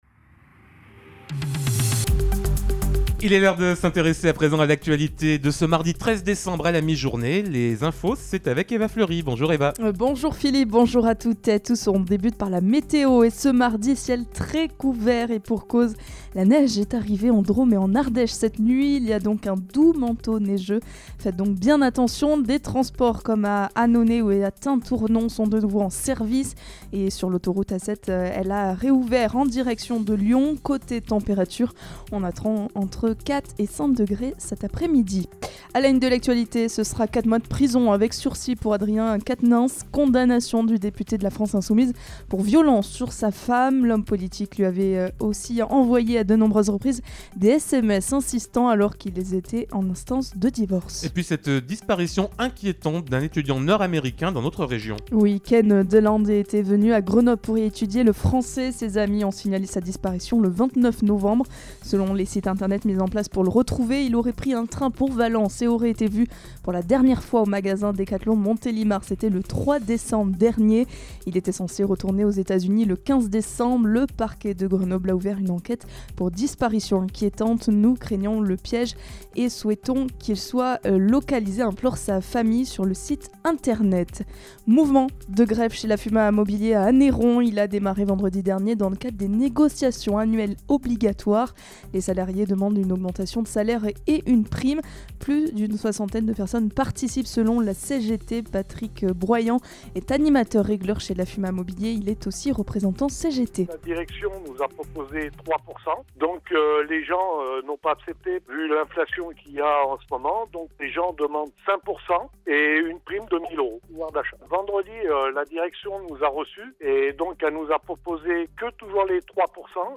Mardi 13 décembre : Le journal de 12h